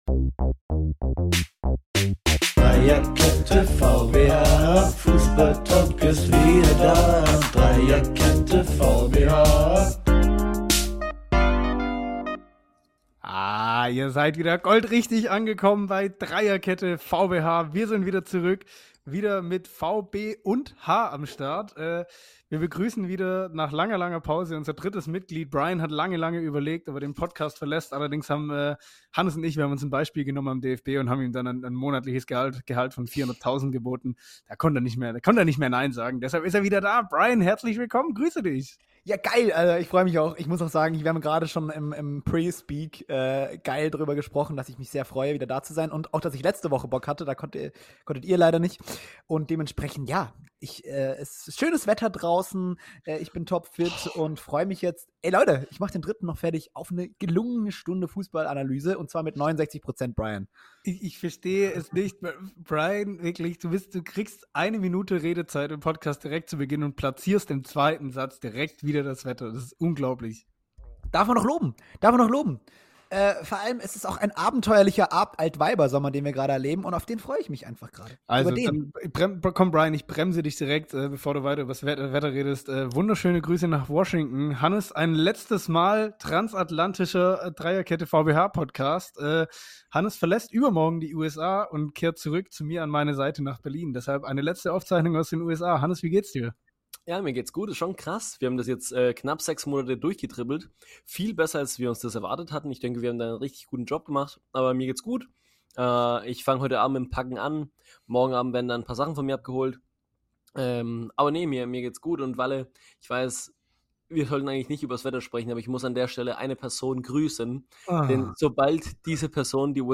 Unsere letzte transatlantische Folge nach knapp 6 Monaten USA-Deutschland Connection. Viel Halbwissen, viel Humor und überraschend viel Graugänse. Es war eine Aufnahme wie sie nur drei selbsternannte Fußballexperten aus Ulm, Freiburg und Ecuador hinbekommen können.